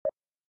error.m4a